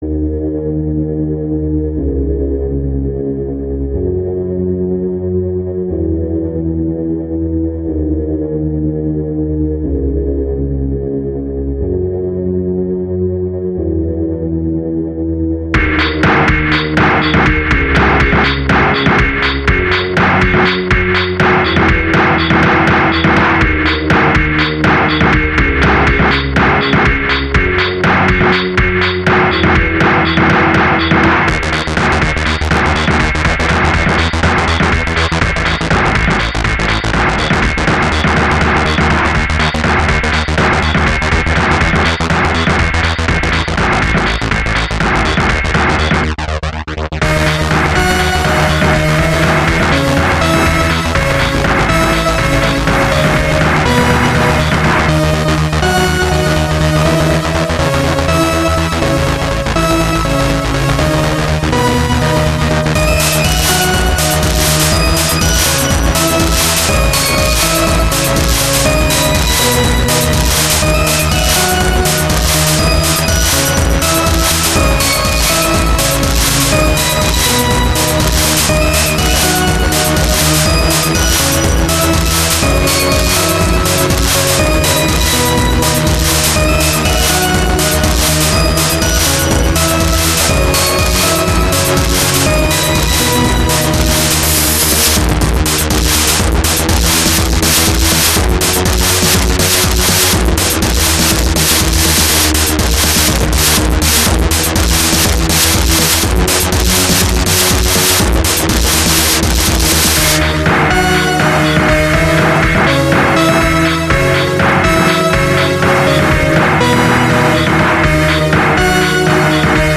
Distorted Vision[ebm mp3]
but the old grendel was good and EVP was a favorite of mine and your song sounds a bit like it.
Beats are great.